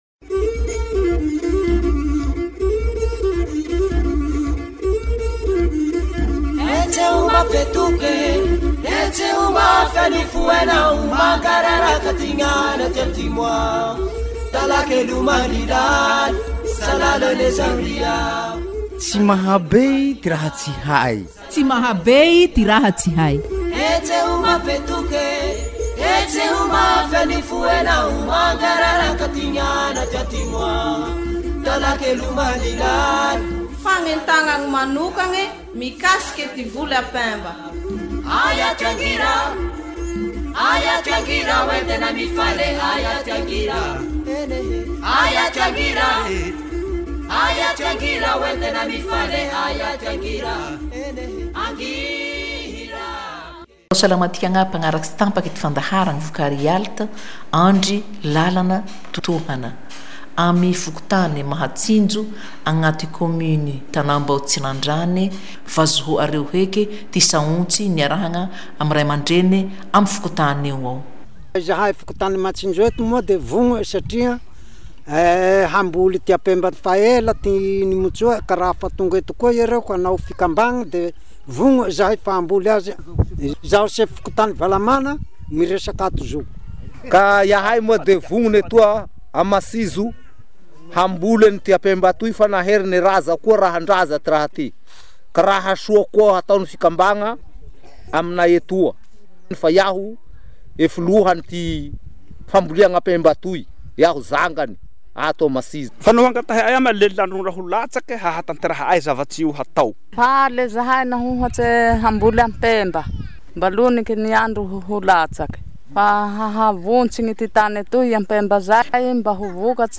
Emissions radiophoniques
Assemblée générale FKT Mahatsinjo TNT (réunion villageoise)